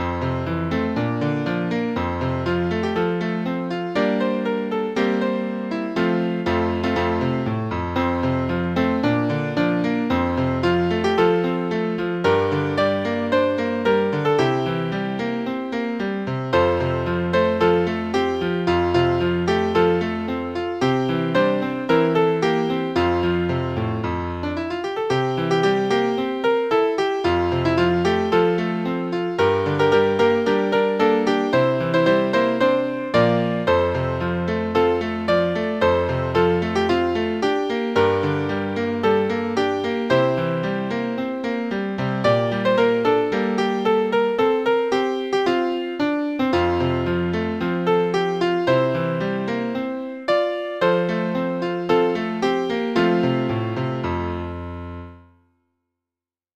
１番のみ/６和音 吹奏楽フルスコア